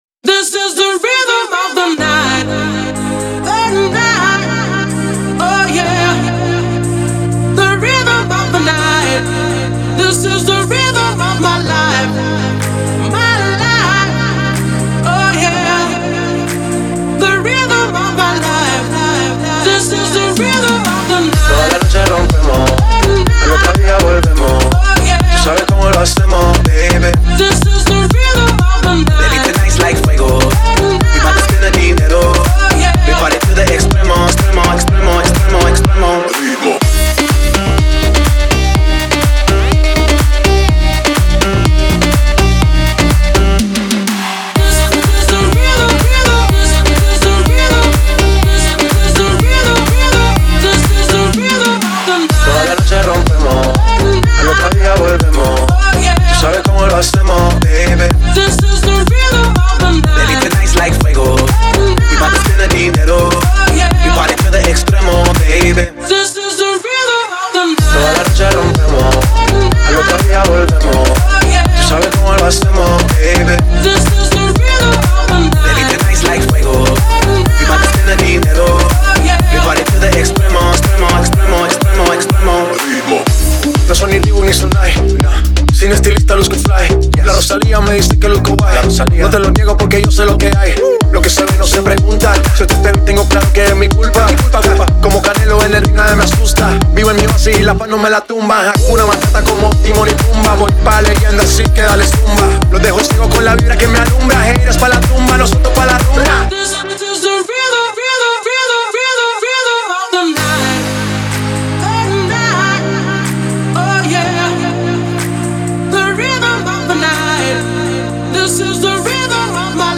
это зажигательная композиция в жанре поп и хип-хоп